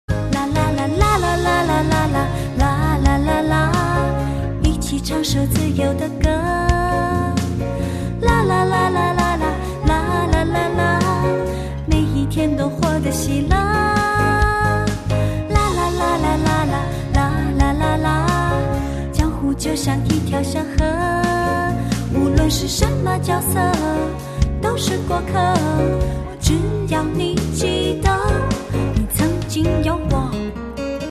M4R铃声, MP3铃声, 华语歌曲 70 首发日期：2018-05-14 22:29 星期一